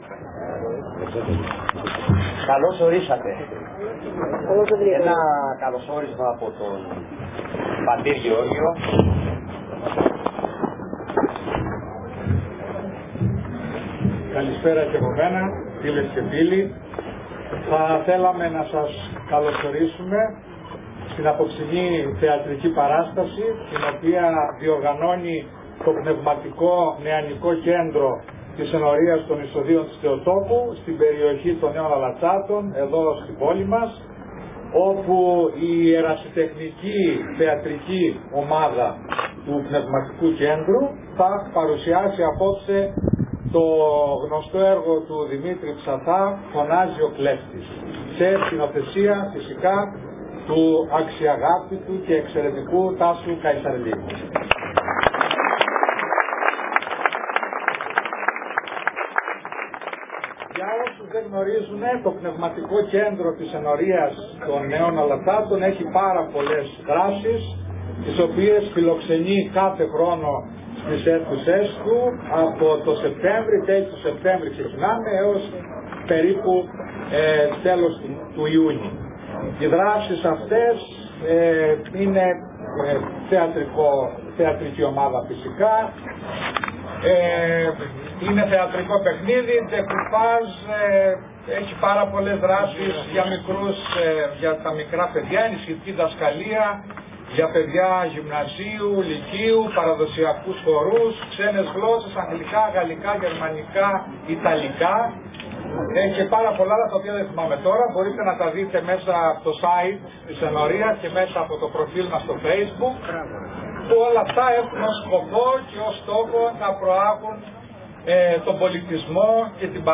Η ερασιτεχνική θεατρική ομάδα του Πνευματικού-Νεανικού Κέντρου
Η παράσταση ανέβηκε στο Κηποθέατρο της “Πύλης Βηθλεέμ” των Ενετικών Τειχών στον Δήμο Ηρακλείου στις 2 και 3 Αυγούστου 2025.